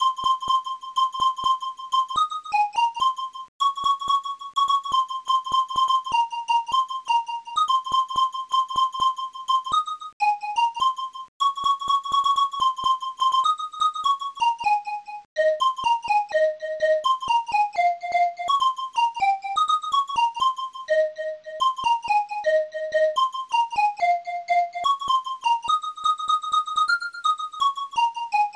st-02:bassdrum3